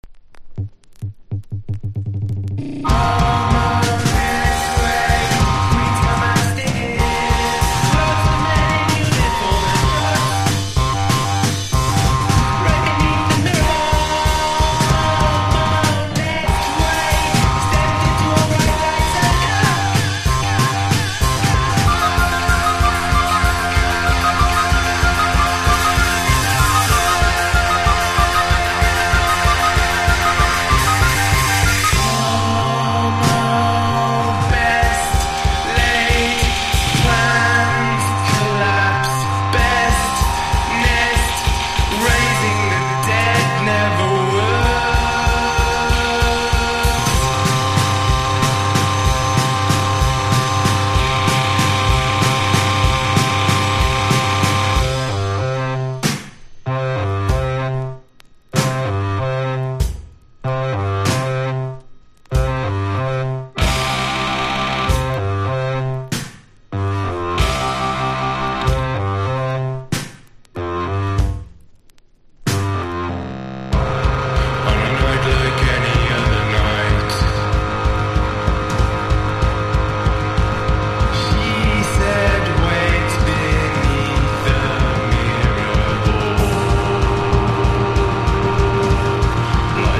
ALTERNATIVE / GRUNGE